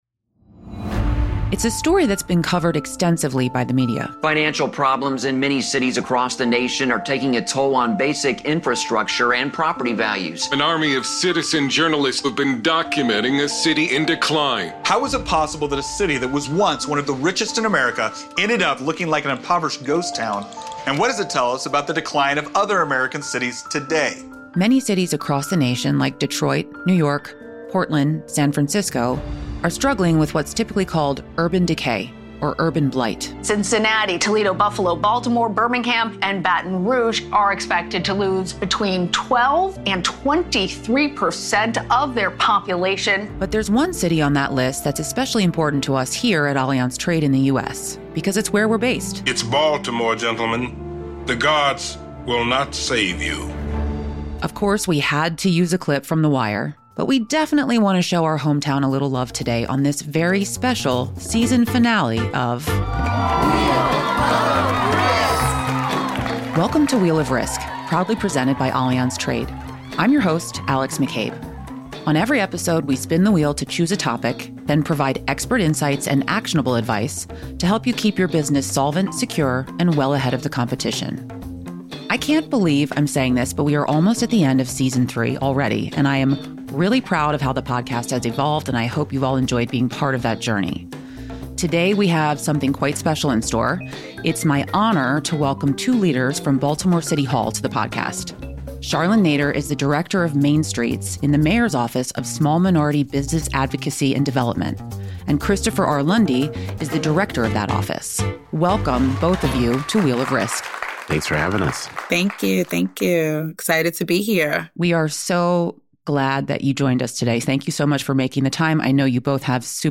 comprehensive conversation about efforts to reinvigorate the local economy